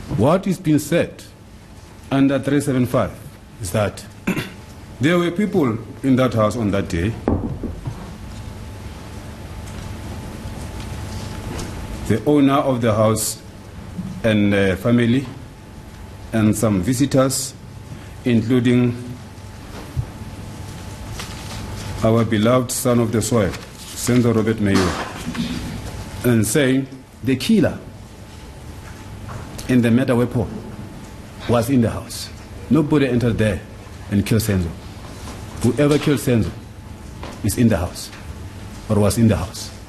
Listen to some of the audio from the trial: